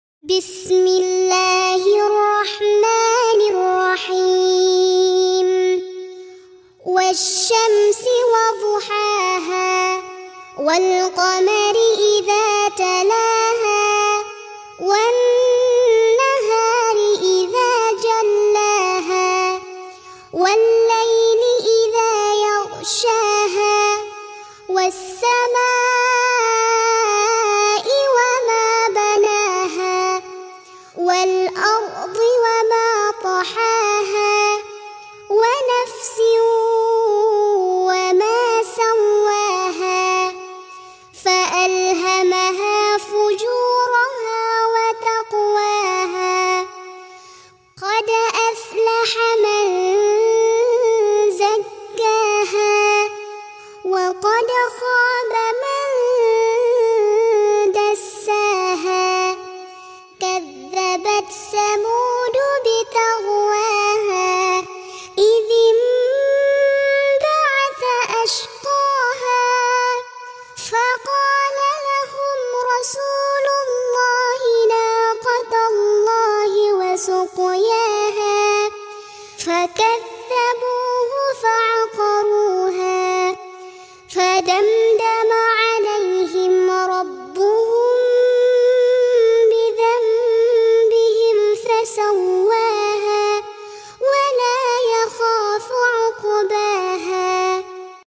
Ngaji Murottal Anak Juz Amma.